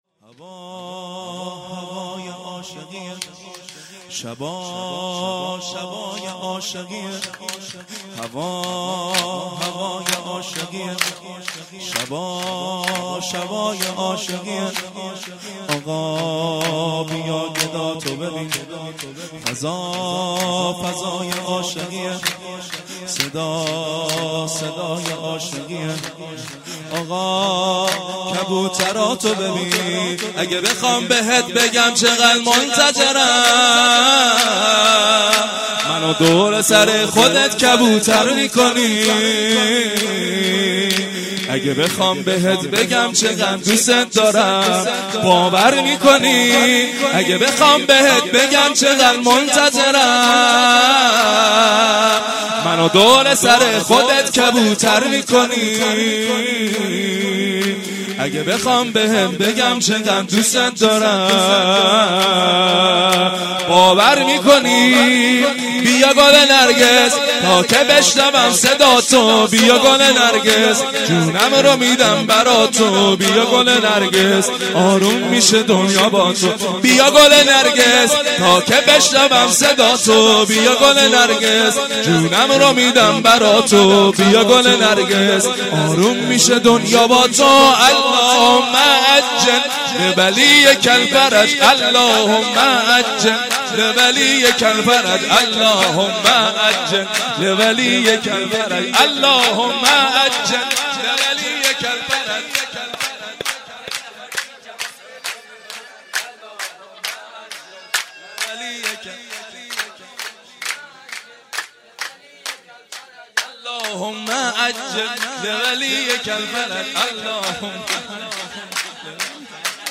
سرود ـ هواهوای عاشقیه